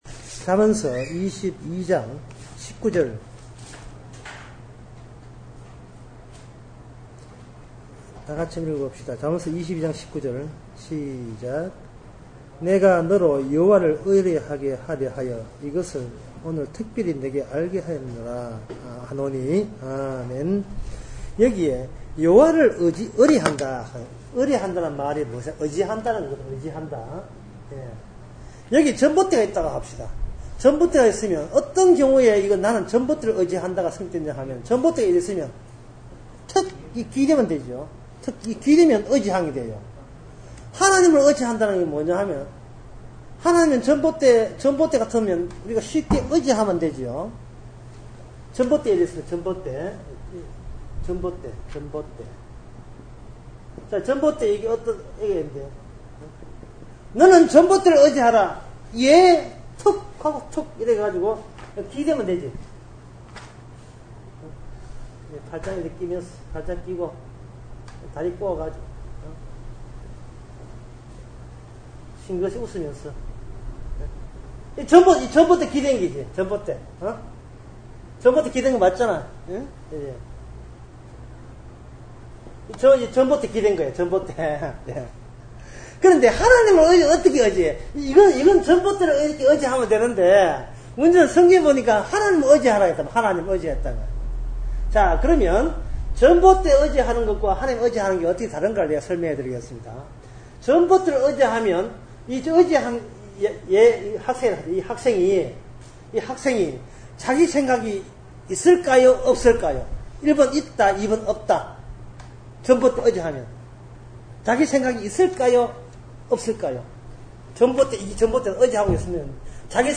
중고등부 설교